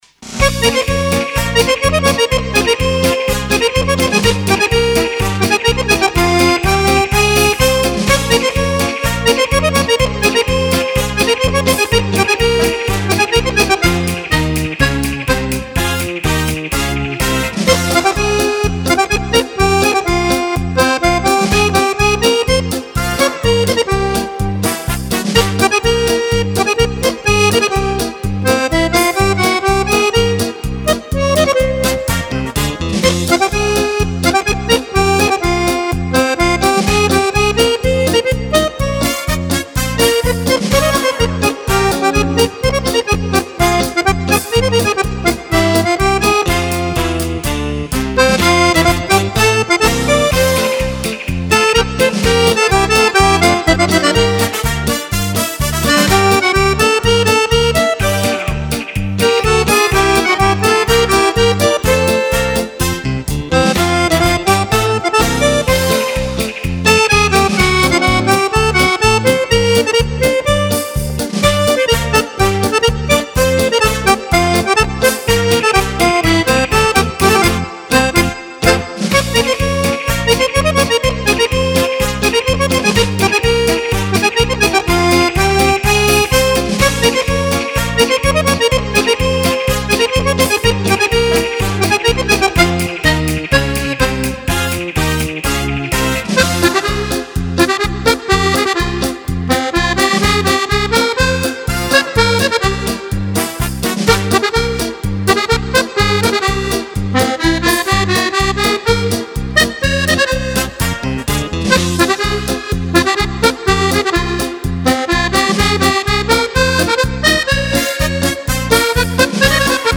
Paso doble